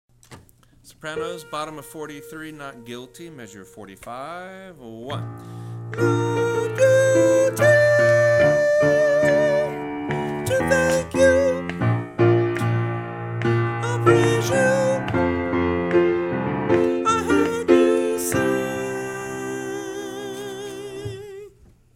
Not Guilty individual voice parts